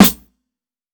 TC SNARE 12.wav